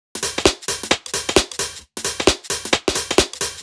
Index of /musicradar/uk-garage-samples/132bpm Lines n Loops/Beats